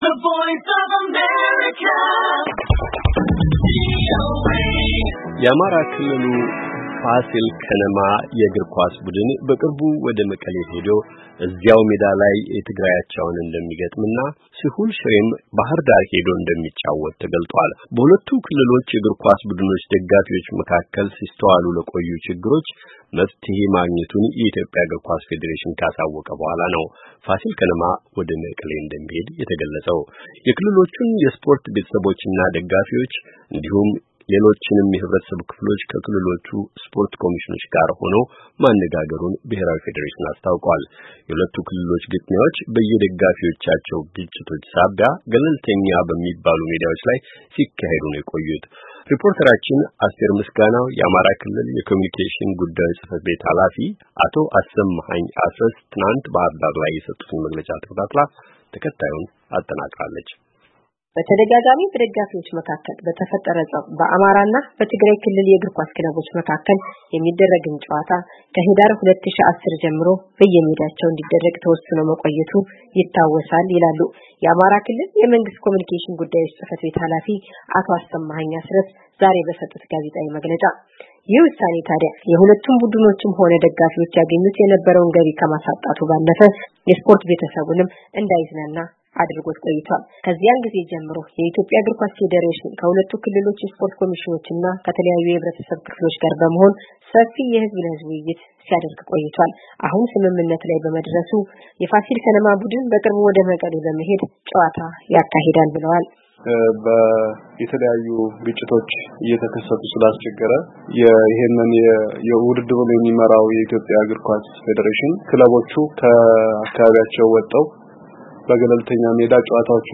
ስፖርት የሰላም፣ የአብሮነት የወዳጅነት ማሳያ ነው የፖለቲካ ማራመጃ አይደለም ሲሉ የአማራ ክልል የኮሙዩኒኬሽን ጉዳዮች ጽ/ቤት ኃላፊ አቶ አሰማኸኝ አስረስ ዛሬ በሰጡት ጋዜጣዊ መግለጫ ገለፁ፡፡